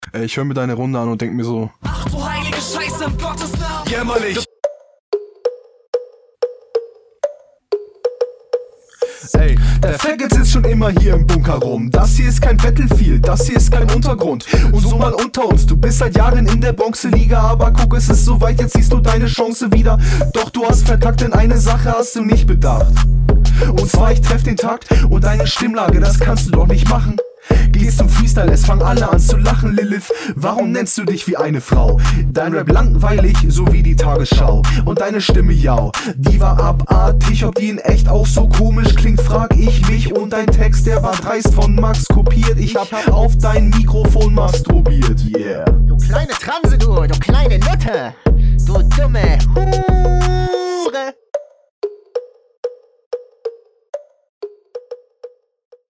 In allen Punkten bis auf Text und Soundquality nur leicht unterlegen.